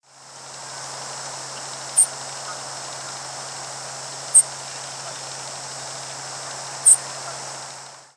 Song Sparrow diurnal flight calls